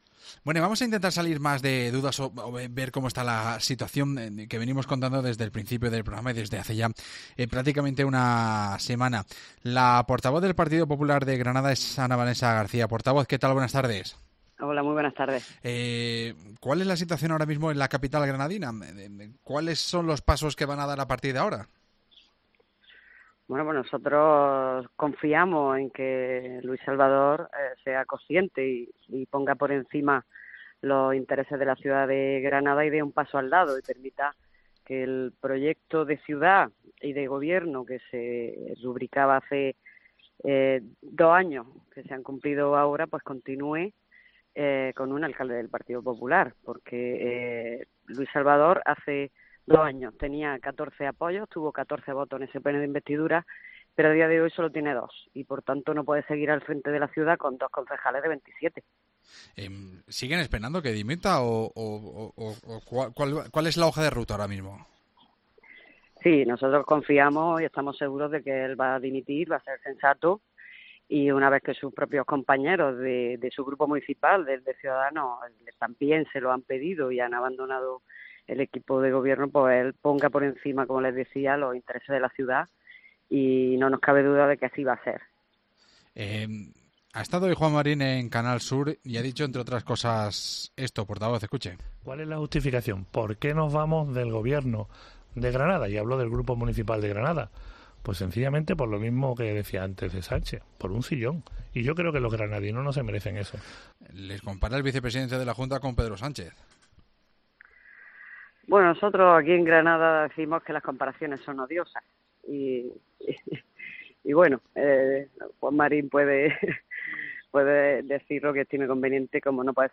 La portavoz del PP granadino, Ana Vanessa García, repasa la actualidad en COPE